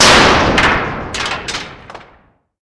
Звонкий удар по металу.